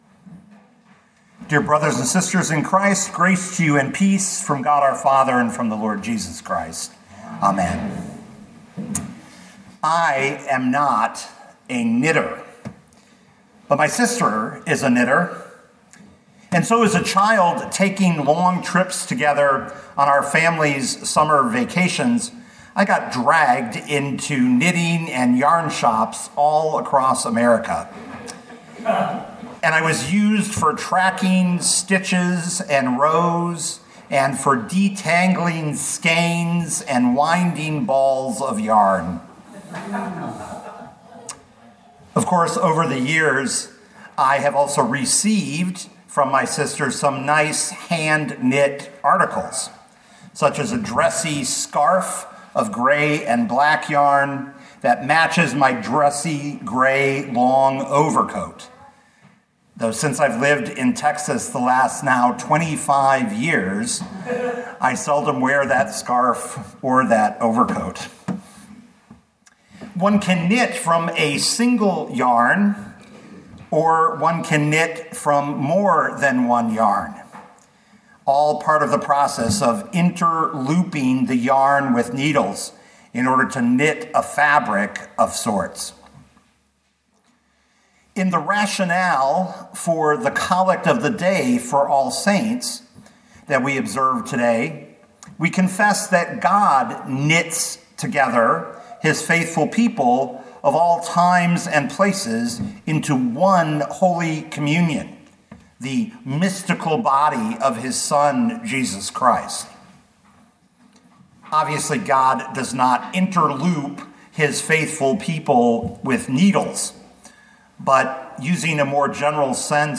2025 1 Corinthians 12:27 Listen to the sermon with the player below, or, download the audio.